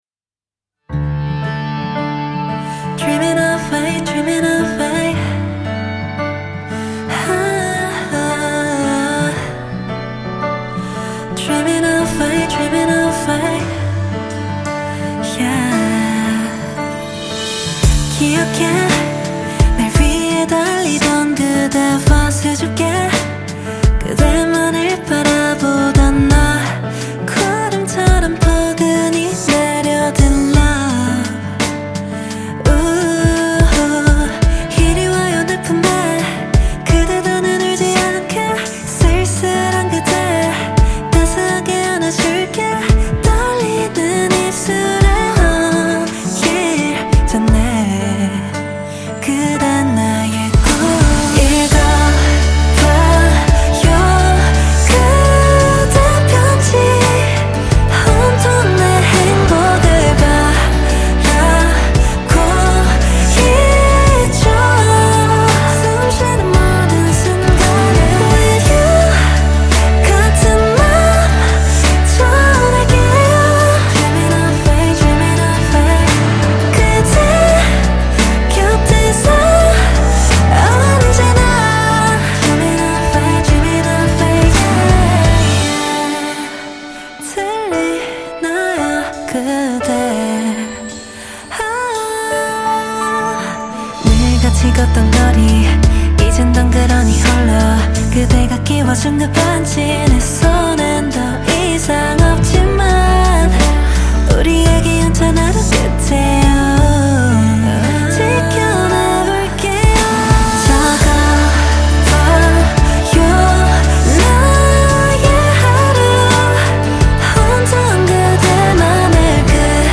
KPop
Label Dance